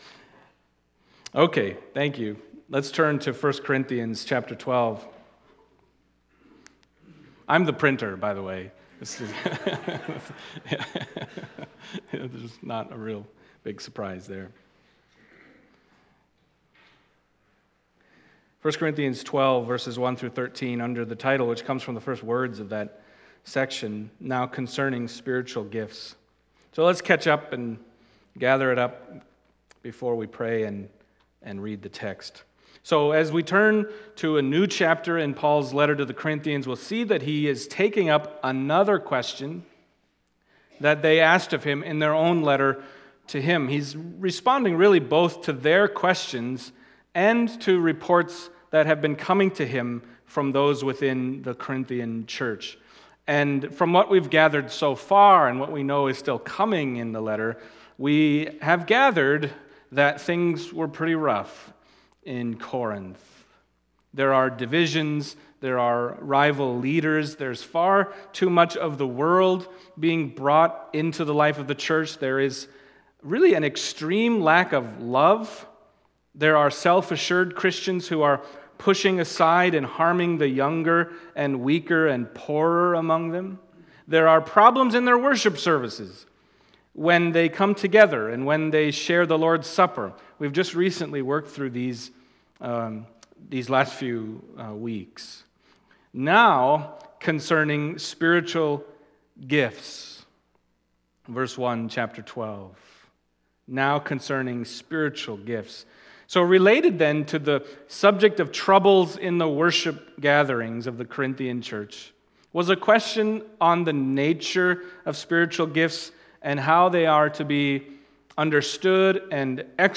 Passage: 1 Corinthians 12:1-13 Service Type: Sunday Morning